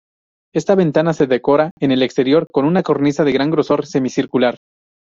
Read more thickness, width Frequency 33k Hyphenated as gro‧sor Pronounced as (IPA) /ɡɾoˈsoɾ/ Etymology From grueso + -or.